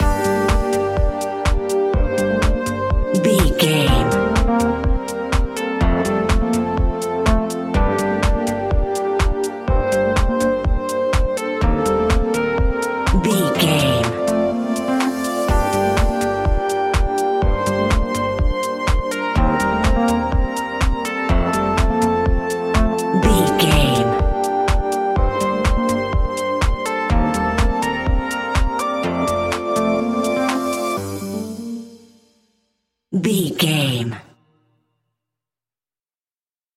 Aeolian/Minor
dark
futuristic
groovy
synthesiser
drum machine
electric piano
funky house
deep house
nu disco
upbeat
synth bass